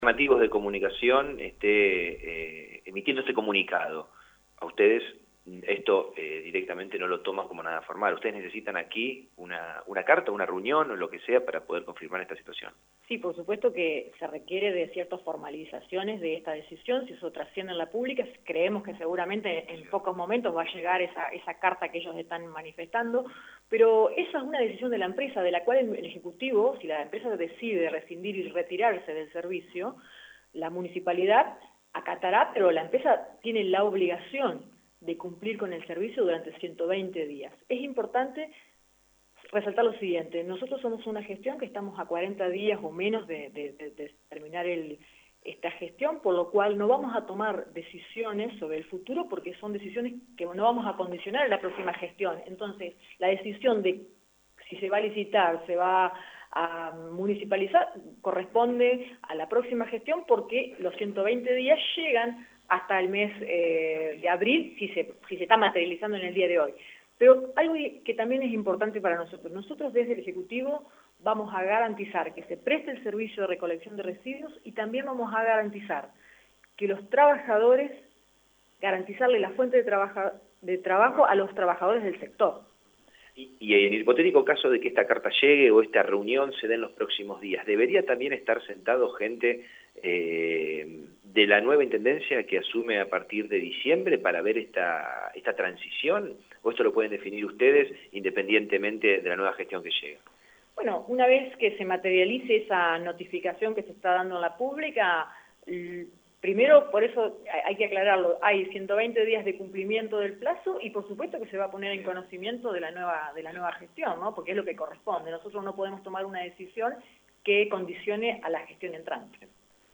Sin embargo, Malena Azario, secretaria de Gobierno local informó en Radio EME que «se require de una formalización que, si trasciende en lo público en poco tiempo llegará al Municipio».